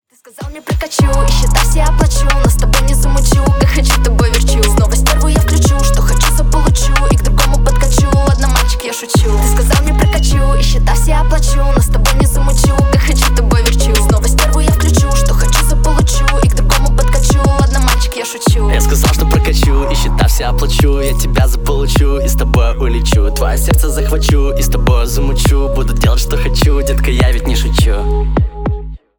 Поп Музыка
весёлые